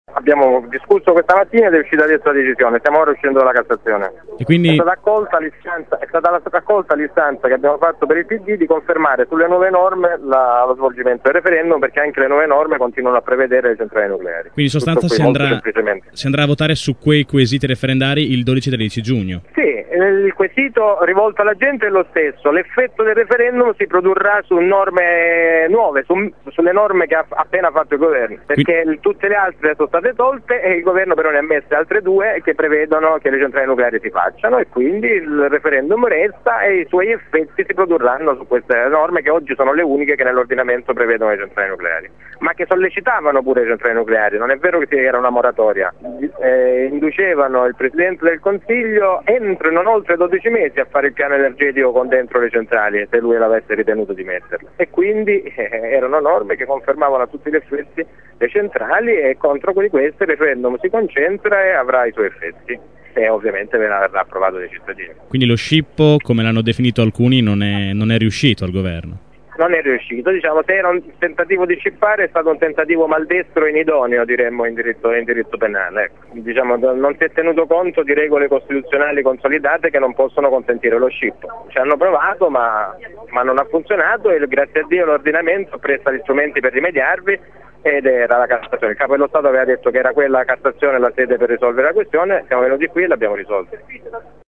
raggiunto telefonicamente mentre esce dal palazzo della Corte di Cassazione.